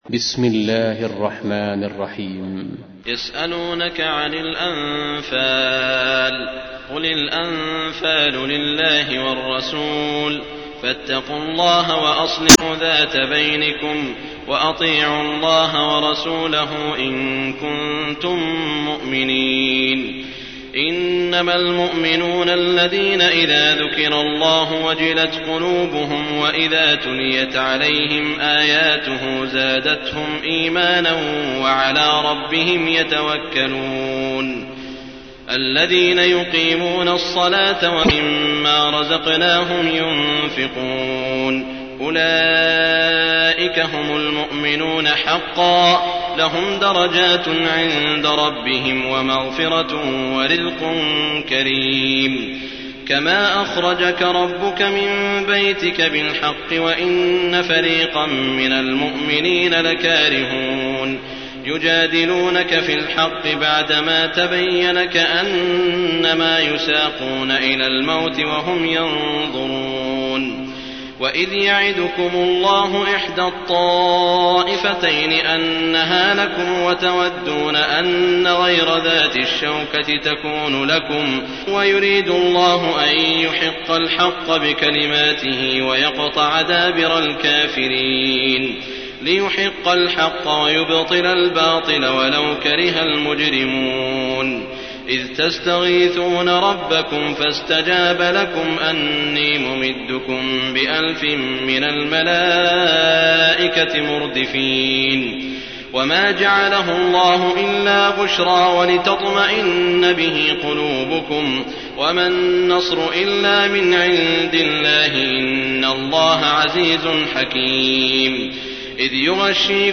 تحميل : 8. سورة الأنفال / القارئ سعود الشريم / القرآن الكريم / موقع يا حسين